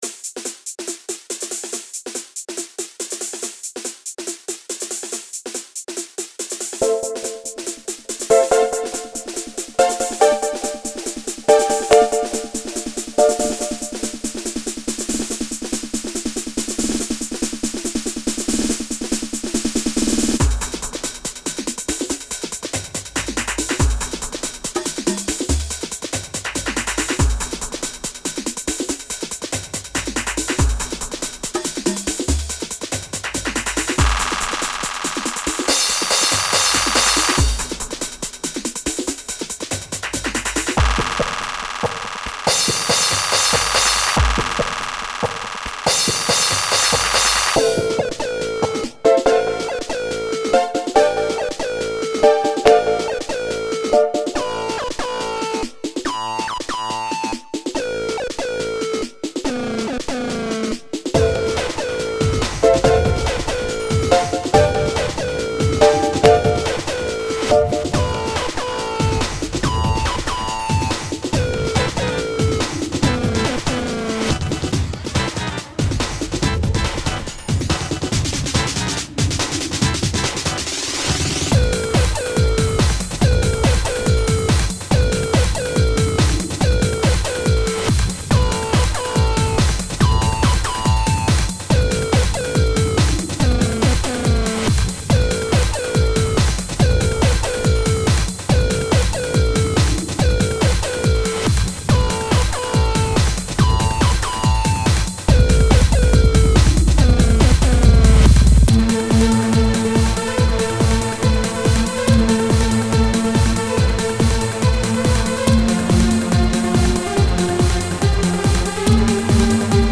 • Жанр: Электронная
слов нету, трек инструментальный